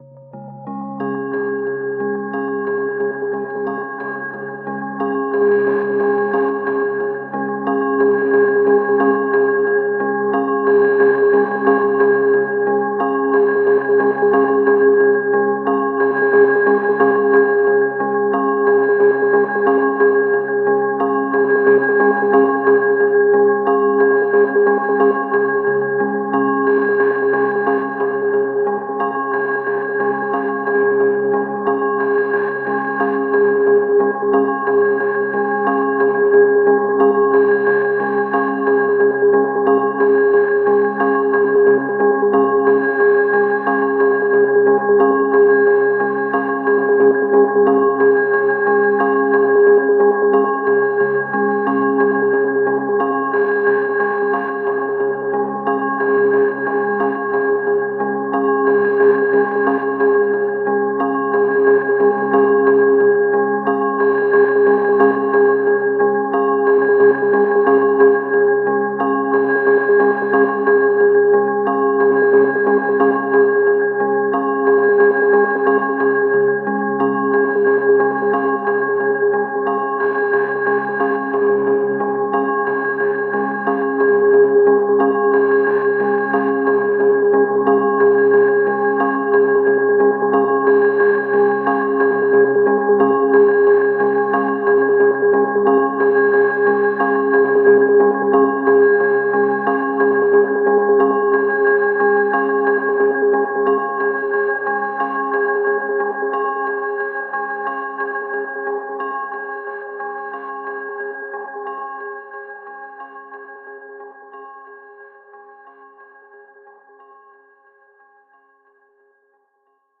Pieza de Ambient
Música electrónica
ambiente
melodía
sintetizador